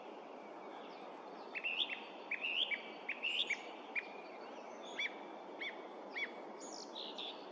Птицы -> Дроздовые ->
певчий дрозд, Turdus philomelos
СтатусПоёт